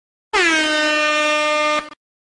Air Horn
air-horn-sound-effect.mp3